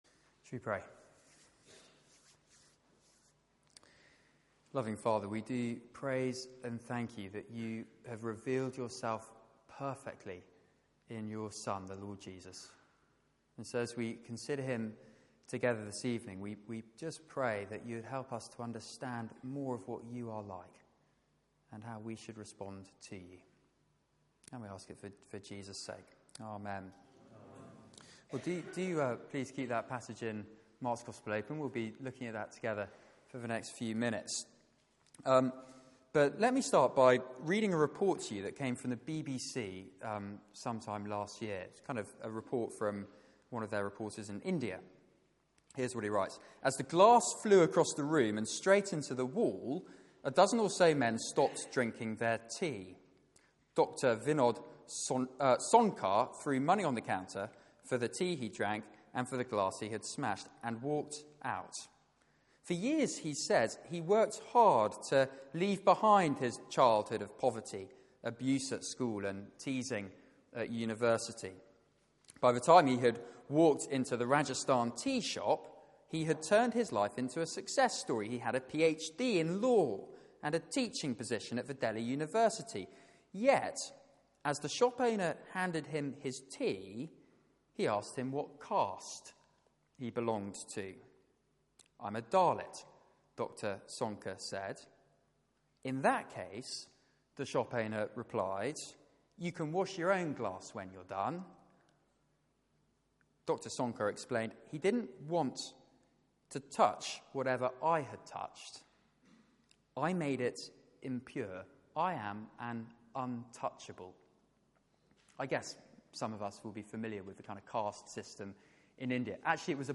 Passage: Mark 7:24-37 Service Type: Weekly Service at 4pm